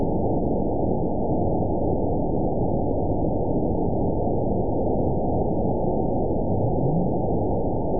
event 922750 date 03/25/25 time 19:34:33 GMT (2 months, 3 weeks ago) score 9.39 location TSS-AB04 detected by nrw target species NRW annotations +NRW Spectrogram: Frequency (kHz) vs. Time (s) audio not available .wav